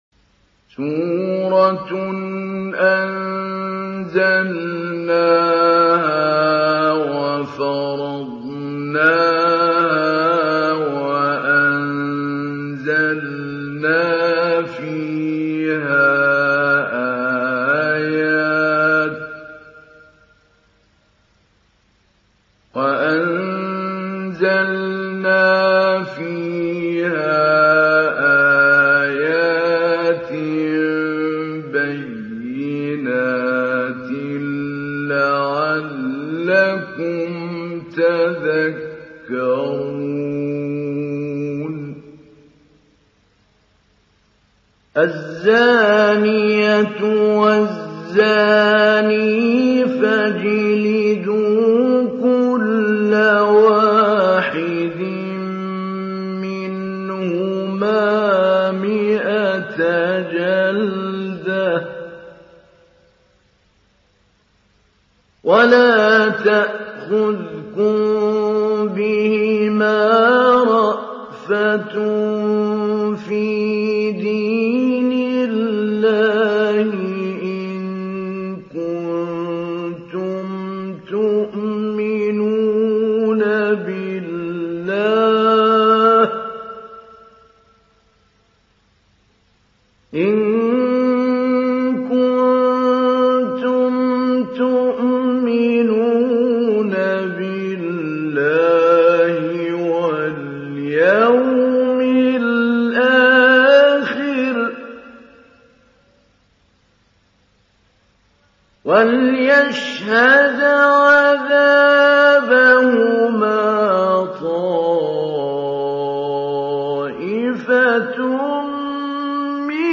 Download Surat An Nur Mahmoud Ali Albanna Mujawwad